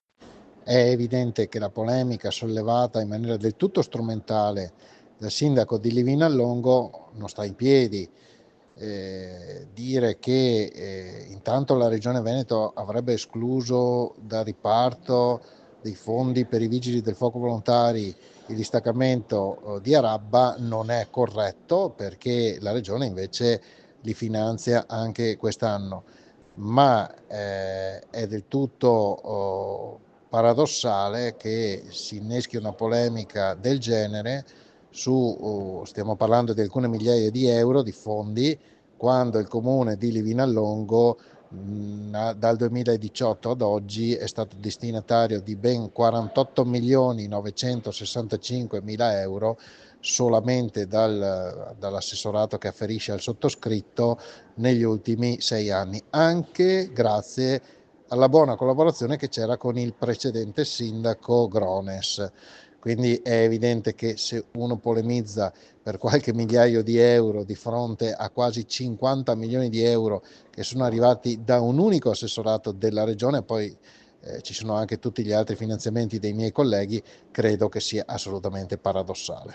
REDAZIONE Nel giorno in cui La Giunta approva l’assegnazione dei contributi ai Distaccamenti volontari dei Vigili del Fuoco L’assessore Bottacin ricorda che i contributi andranno a tutti i trenta distaccamenti presenti sul territorio veneto. Ai microfoni di RADIOPIU l’assessore alla protezione civile risponde alle recenti critiche del sindaco Oscar Nagler.